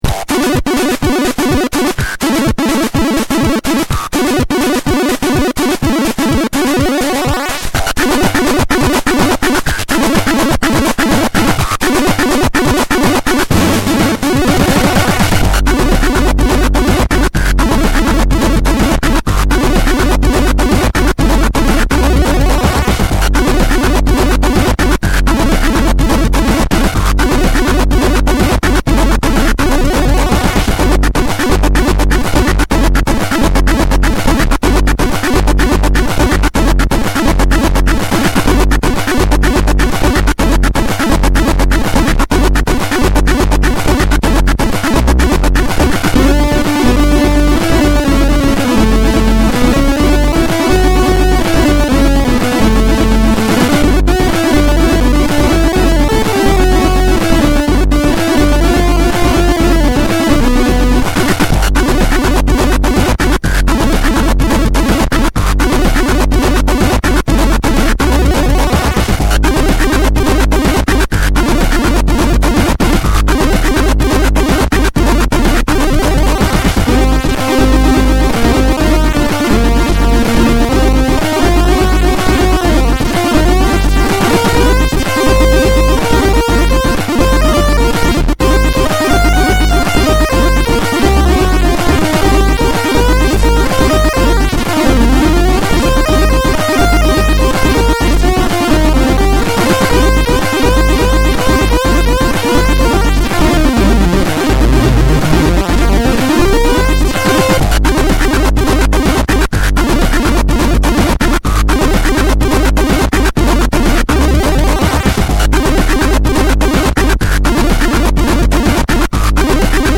This is a 4-speed tune, 200Hz.